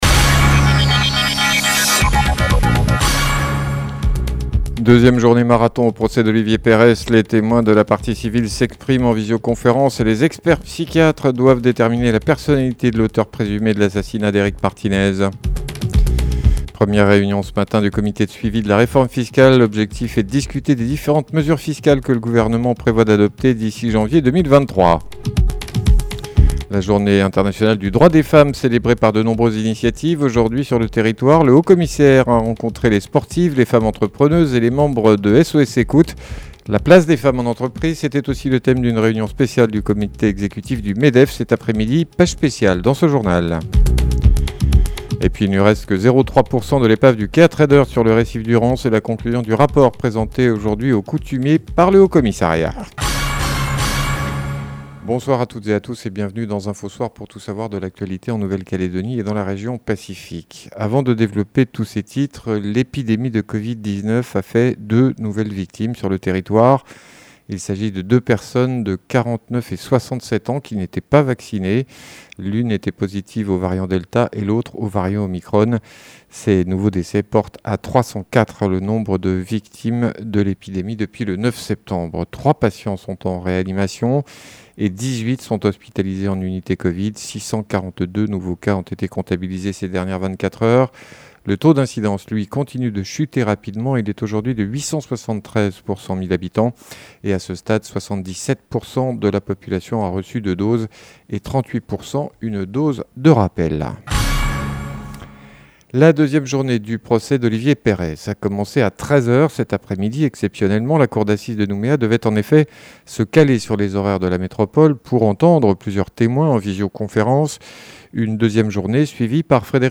JOURNAL : MARDI 08/03/22 (SOIR)